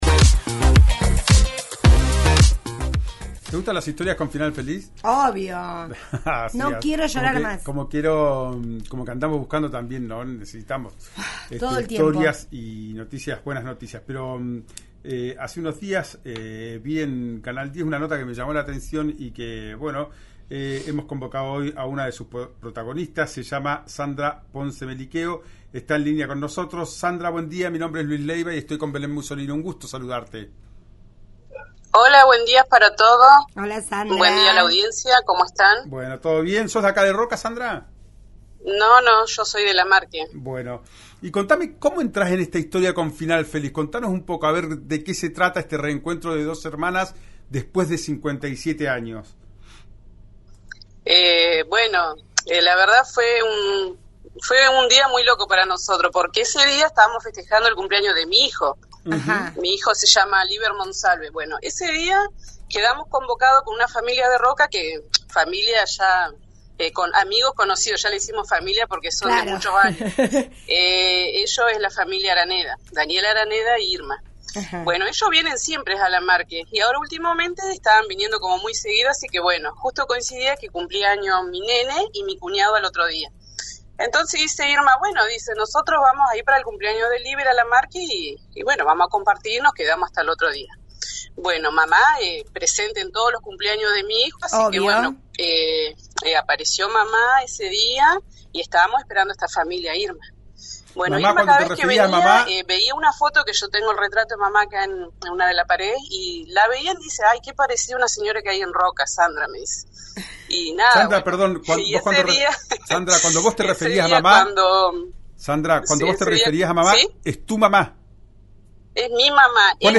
una entrevista realizada por RADIO RÍO NEGRO